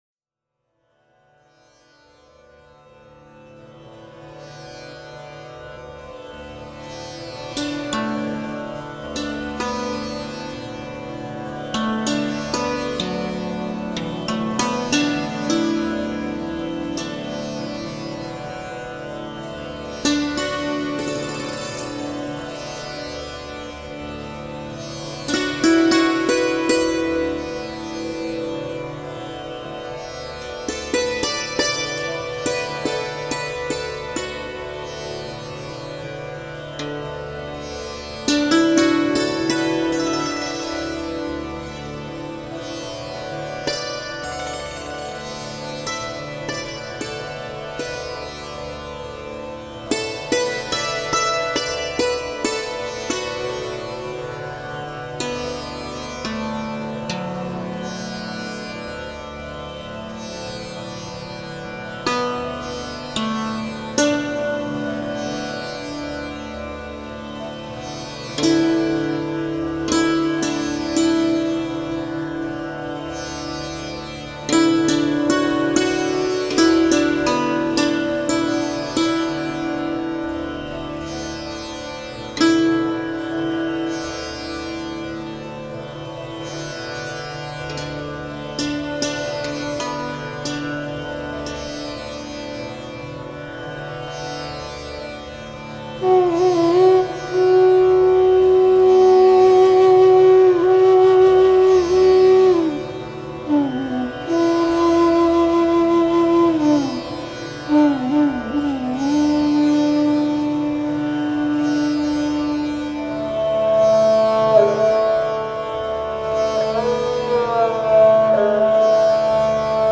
迪尔鲁巴
迪尔鲁巴琴、北印度竹笛、印度千弦琴的深度对话